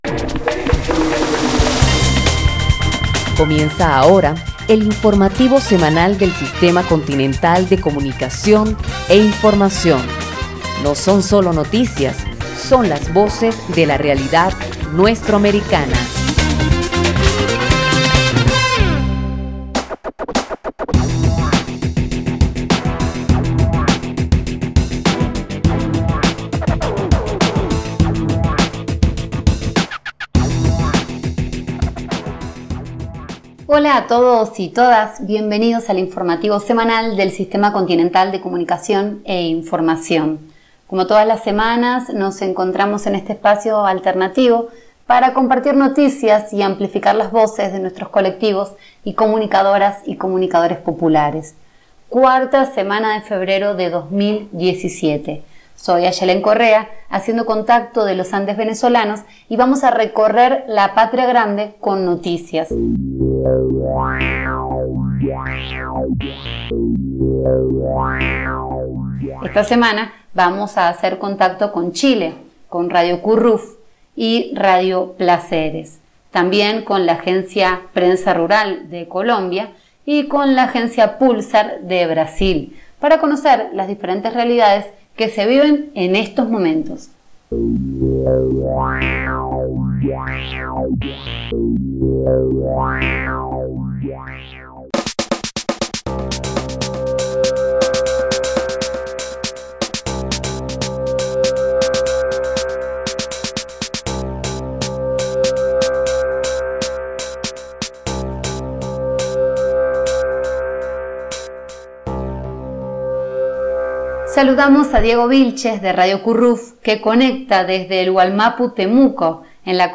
Escucha el Noticiero Semanal Continental SICCI con informaciones de diversos países de Sudamérica - Mapuexpress